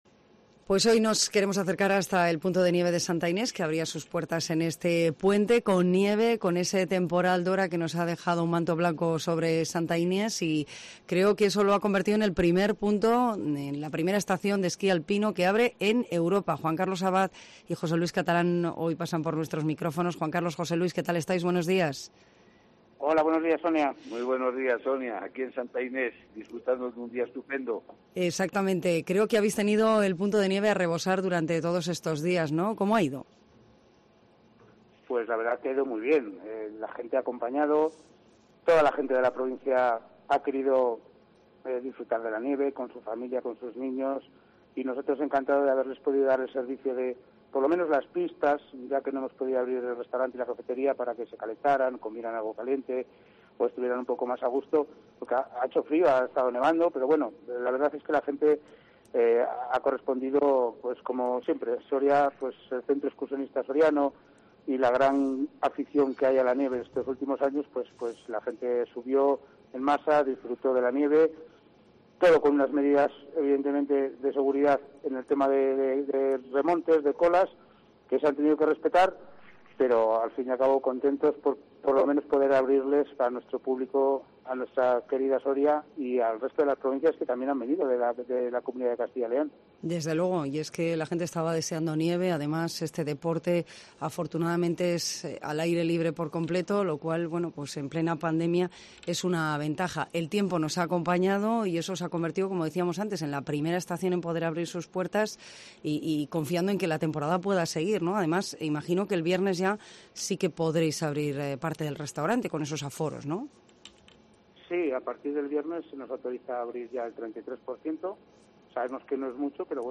AUDIO: Santa Inés, primera estación de esquí alpino de Europa en abrir sus puertas. Escucha la entrevista en Cope